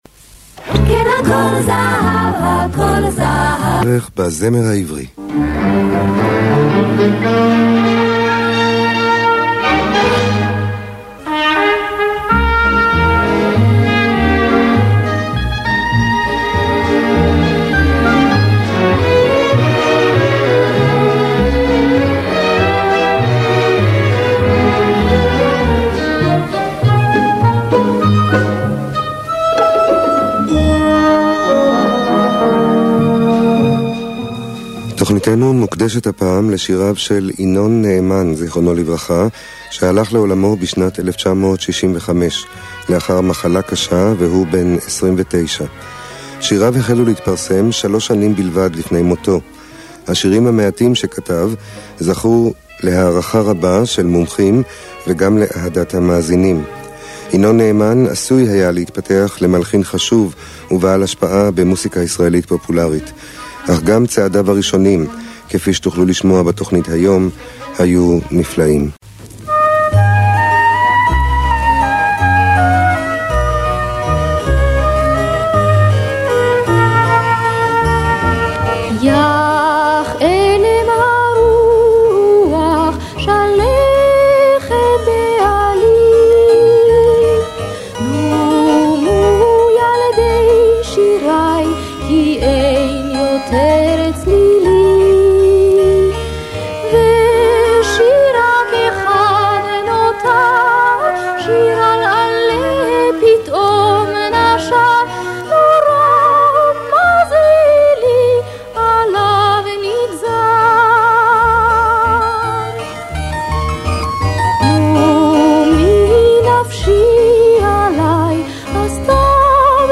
ה ביצוע של זמרים מקומיים ממשמר השרון
כפי שהוקלטה בסביבות 1985 בערב שנעשה לזכרו